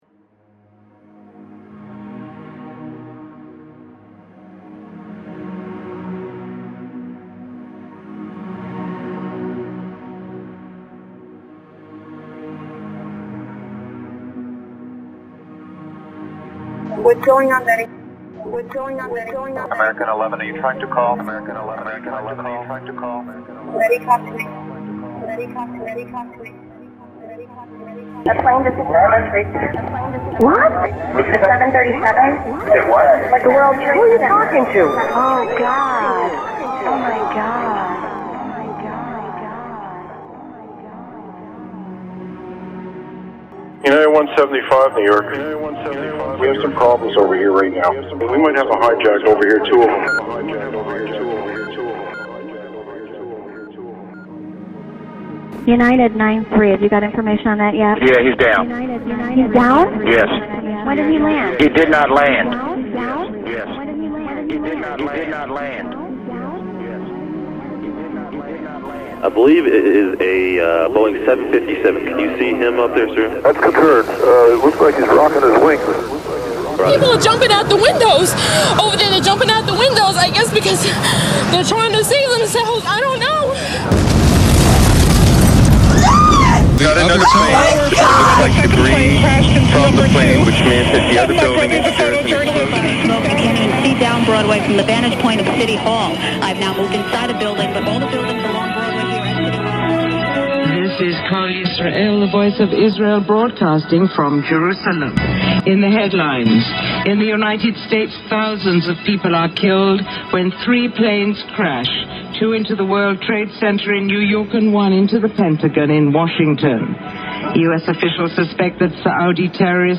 documenting and reimagining the sounds of shortwave radio